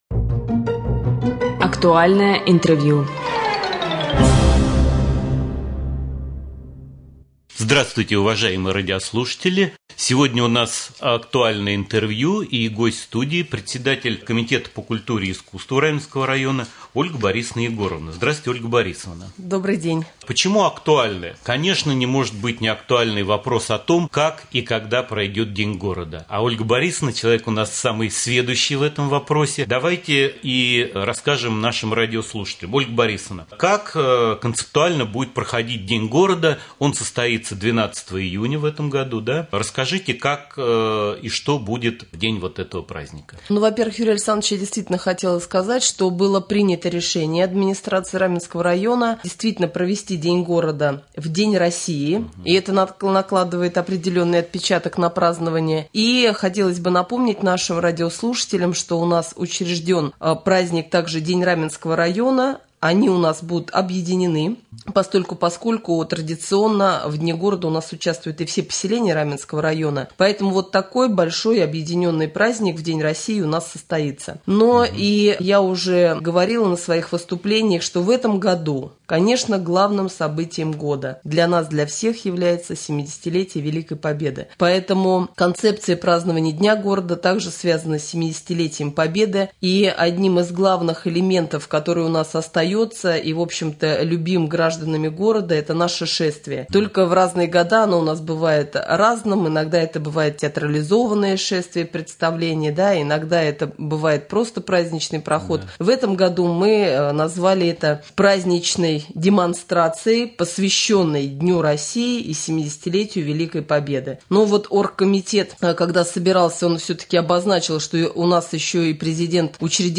В студии Раменского радио председатель комитета по культуре и искусству Ольга Борисовна Егорова.
Актуальное интервью